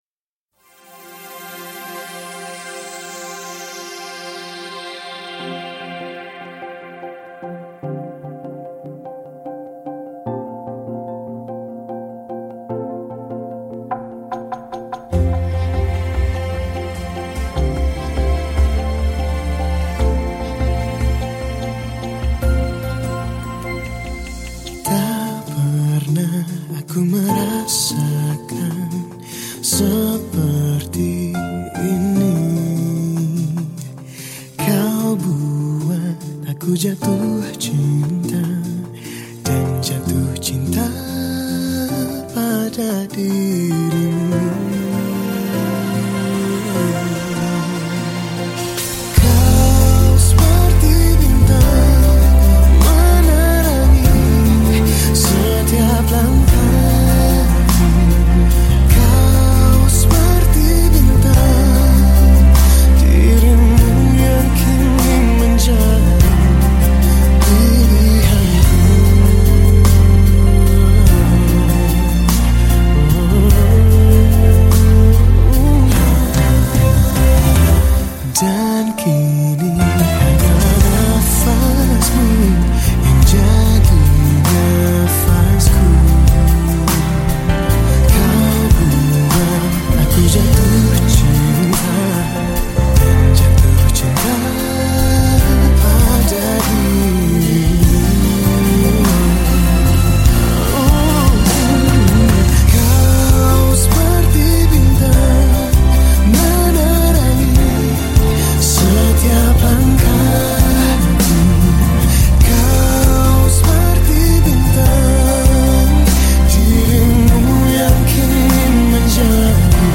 Genre Musik                                 : Musik Pop
Instrumen                                      : Vokal